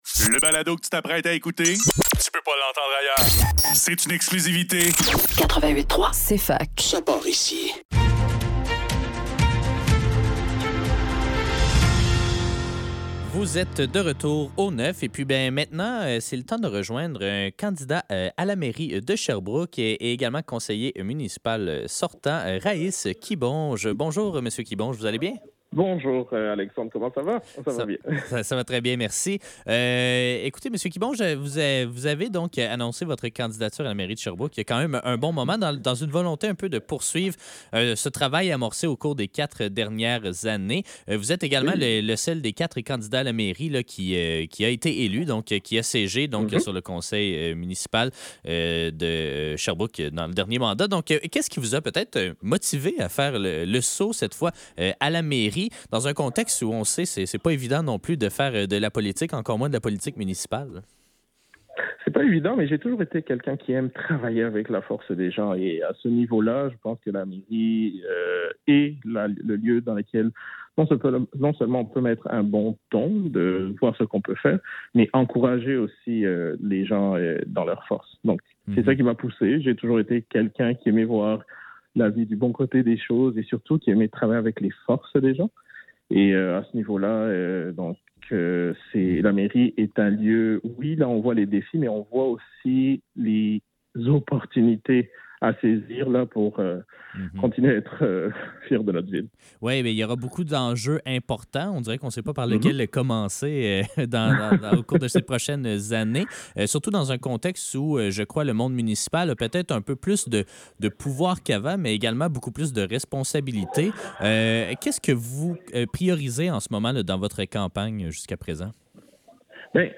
Le neuf - Élections municipales de Sherbrooke 2025: Entretien